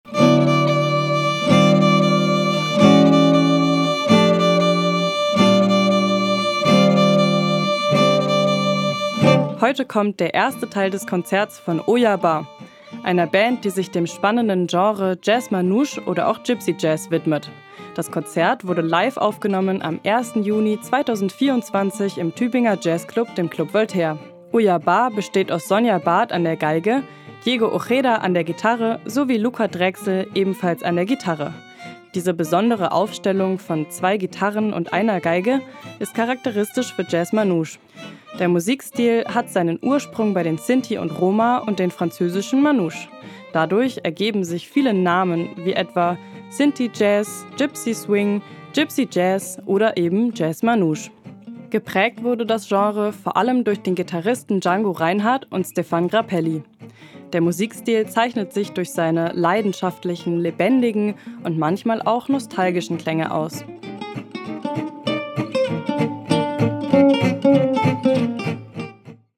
Geige
Gitarre